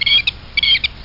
Blackbird Sound Effect
Download a high-quality blackbird sound effect.
blackbird.mp3